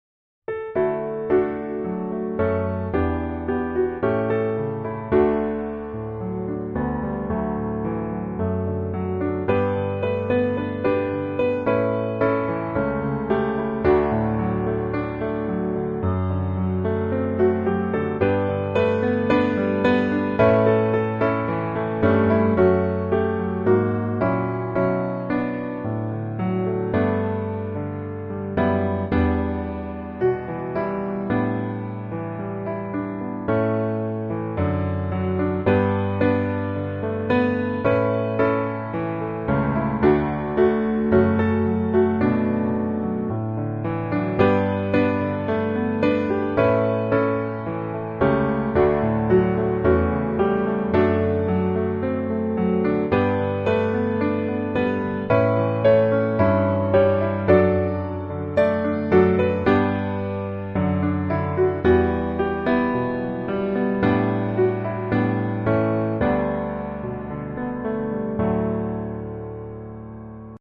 D Majeur